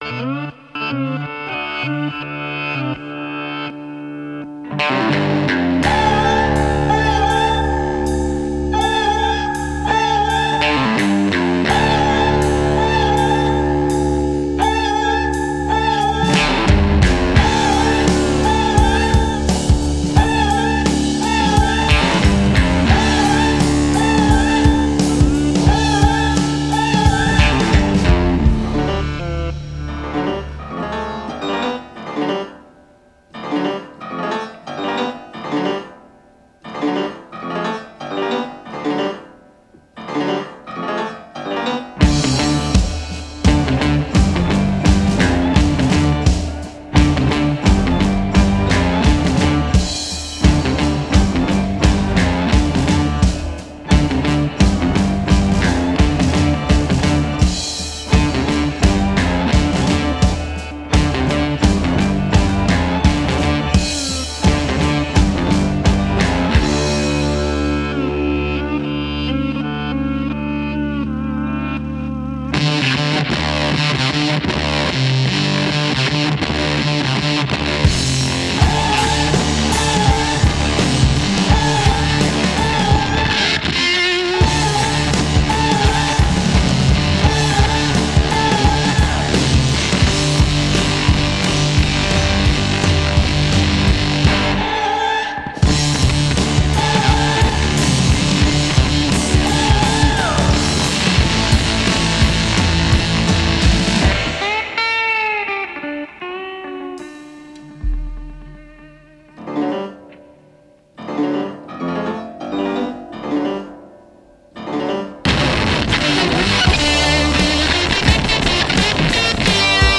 Genre: Blues Rock